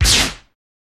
Generic Lasers
Laser Blast Quick 1